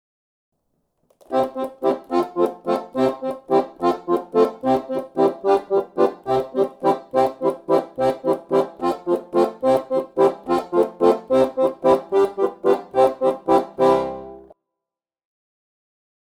Acompañamento
acompanamento_parte_1.mp3